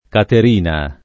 kater&na] (settentr. Catterina [katter&na]) pers. f. — sen. ant. Catarina [katar&na]; ma solo Caterina, oggi, la santa di Siena (1347-80) — rarissimo oggi il masch. Caterino (sen. ant. Catarino: tuttora noto anche in questa forma il teologo senese Ambrogio Caterino o Catarino, al secolo Lancellotto, di cognome Politi o Puliti, m. 1553) — sim. i cogn. Catarin [katar&n], Catarina, -ni, Caterina, -ni, -no, Di Caterina, -ni, -no